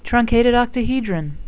(trun-cat-ed oct-a-he-dron)